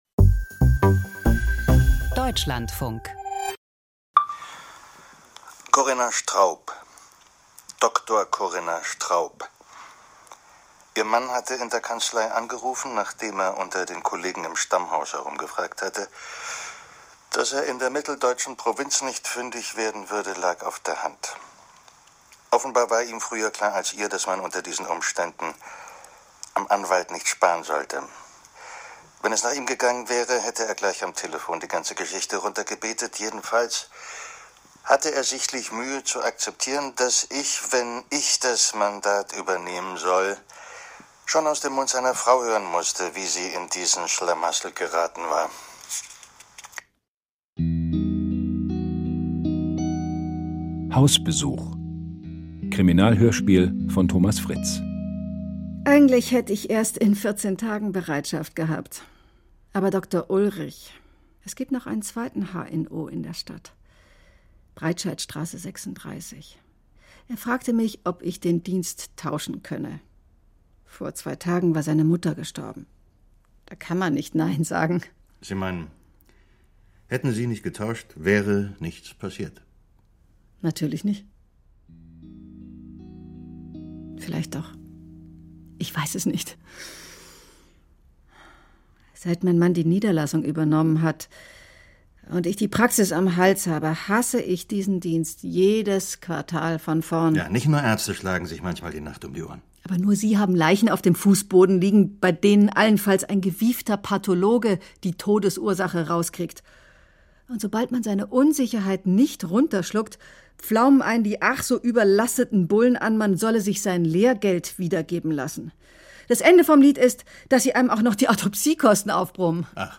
Krimi-Hörspiel: Eine Ärztin ermittelt - Hausbesuch